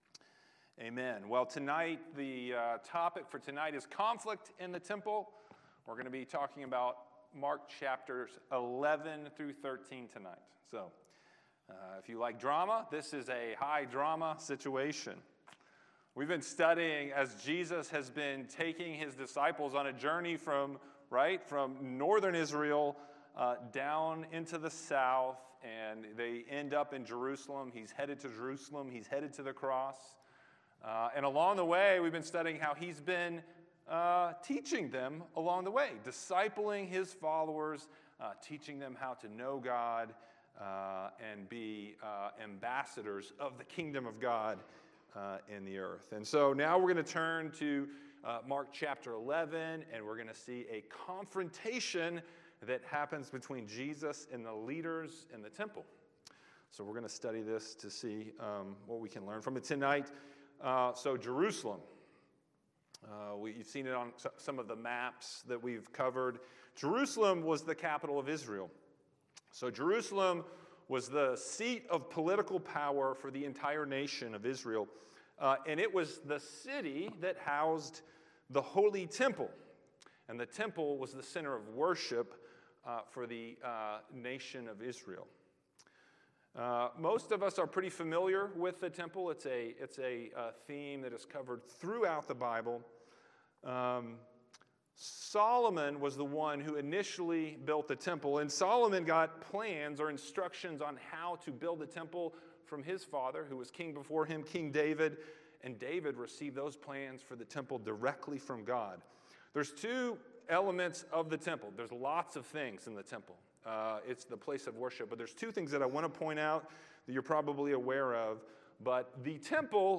Weekly Sermons from Lexington Christian Fellowship